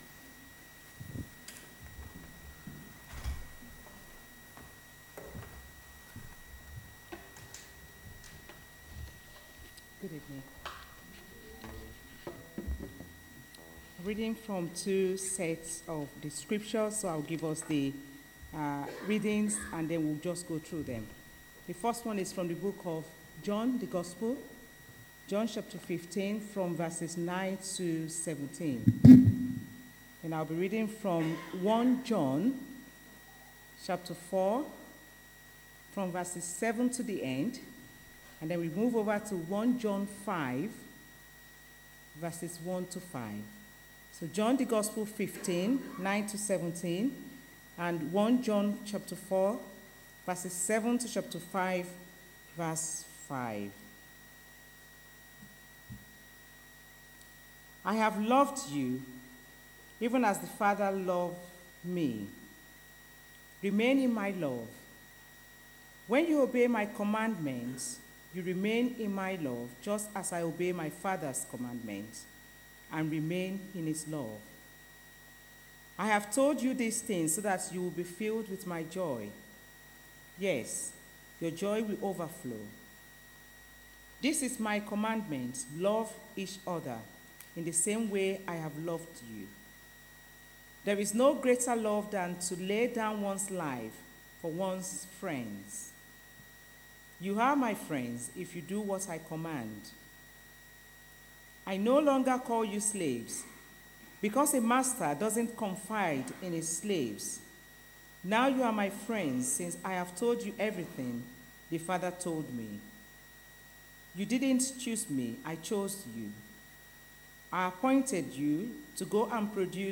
A message from the series "Communion service."